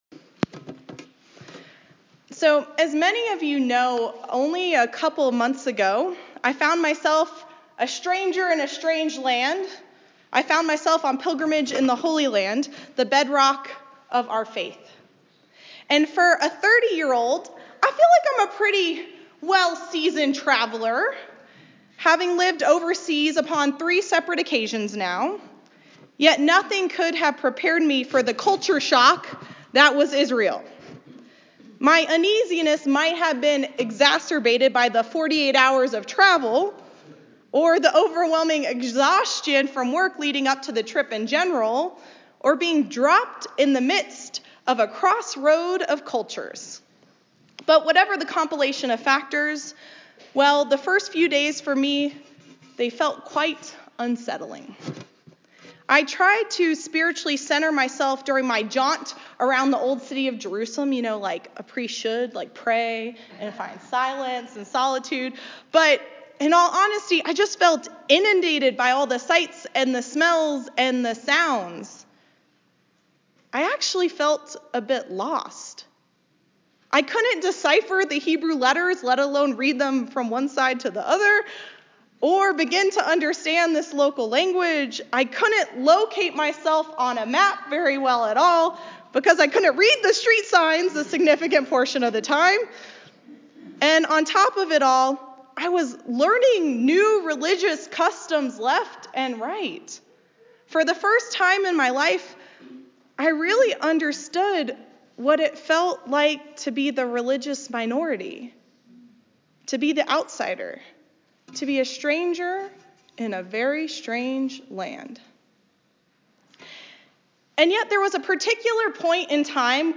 A Sermon for the Eve of the Epiphany.